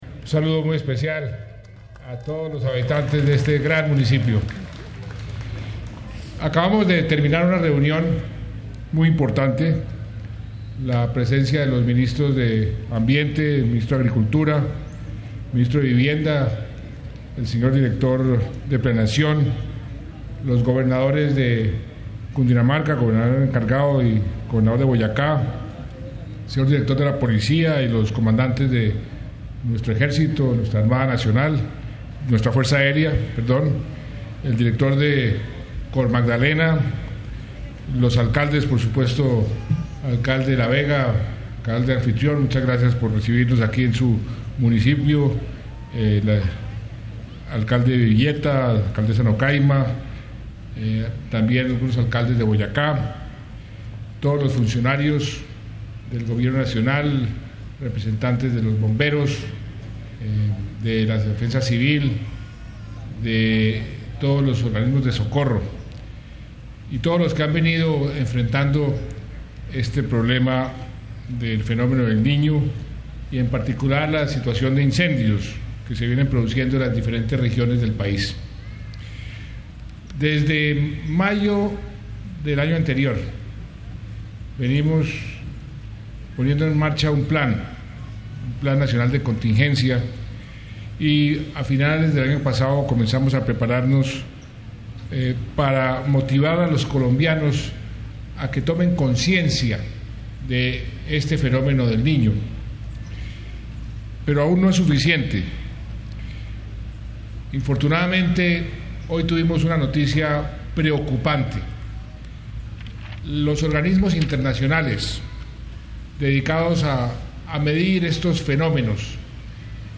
En una declaración a los medios de comunicación desde el municipio cundinamarqués de La Vega, donde se han producido incendios, el Jefe del Estado pidió a los alcaldes y gobernadores del país trabajar de manera coordinada con el Gobierno Nacional